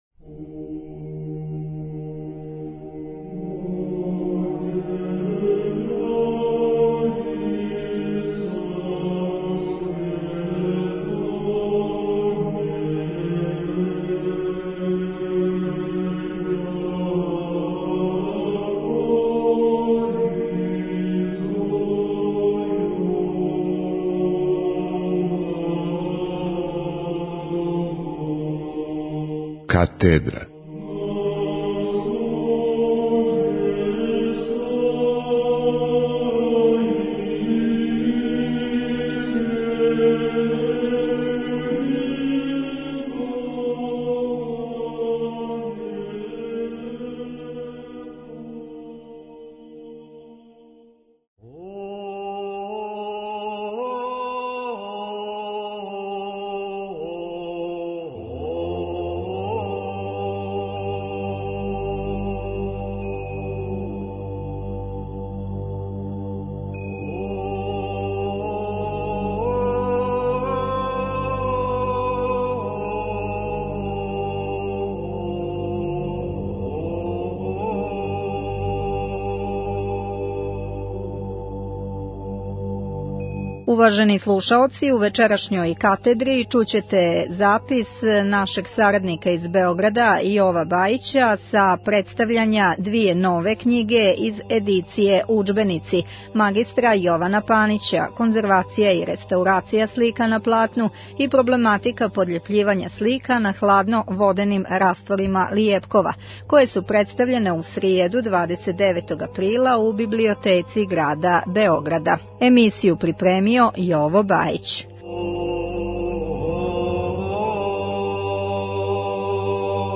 у цркви Св. Марка у Београду